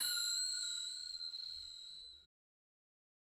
Bell Ding Ring Ting sound effect free sound royalty free Sound Effects